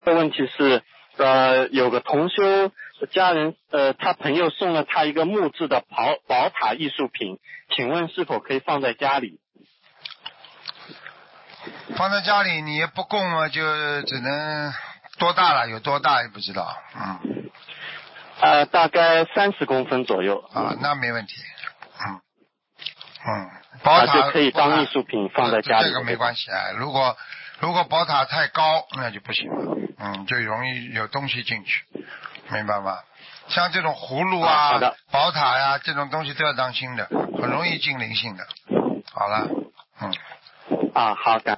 男听众